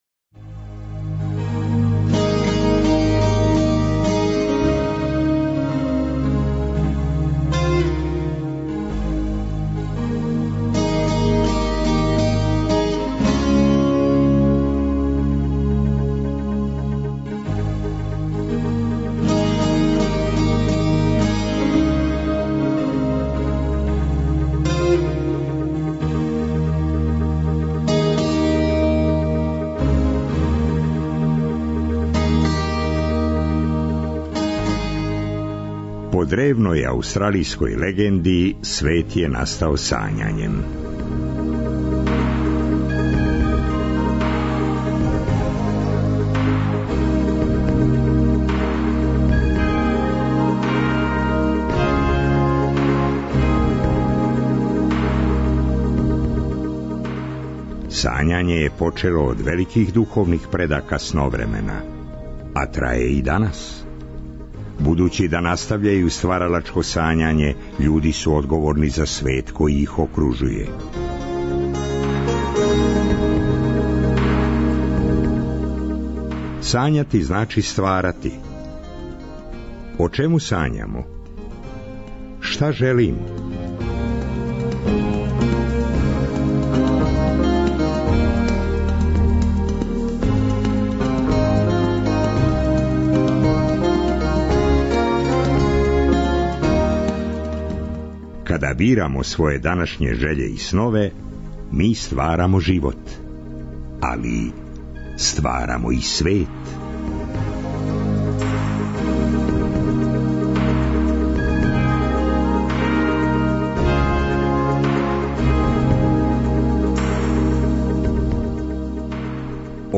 У трећем и четвртом сату Сновремена, уз добру музику, очекују нас бајке, басне и занимљивости о нашим четвороножним пријатељима.